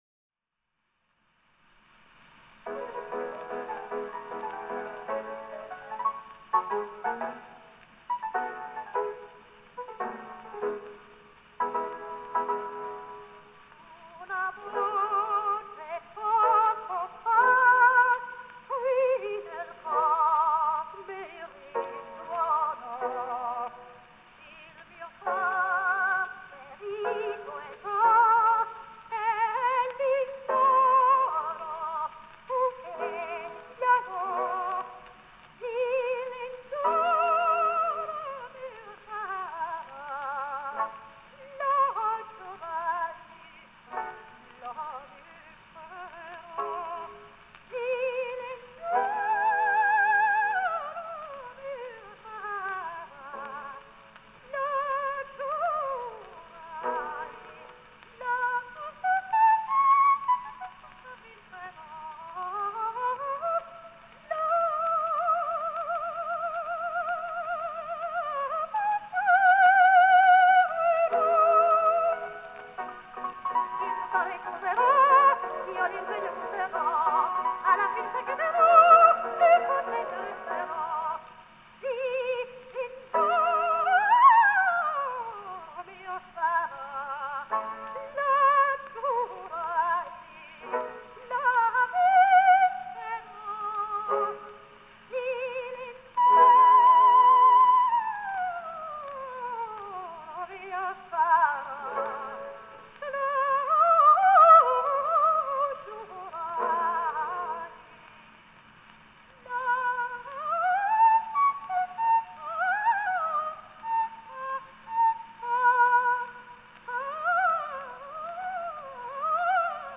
ТЕТРАЦЦИНИ (Tetrazzini) Луиза (29, по др. данным, 28 VI 1871, Флоренция - 28 IV 1940, Милан) - итал. певица (колоратурное сопрано).